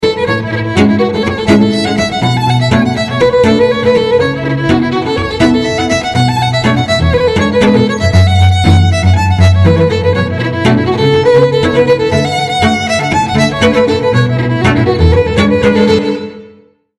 A beautiful tune